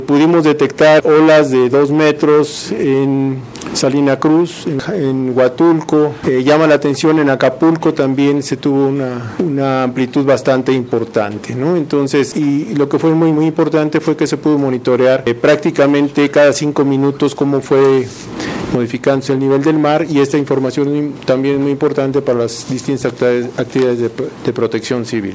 en la conferencia de medios.